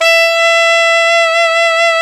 Index of /90_sSampleCDs/Roland L-CDX-03 Disk 1/SAX_Alto 414/SAX_Alto mf 414
SAX ALTOMF0J.wav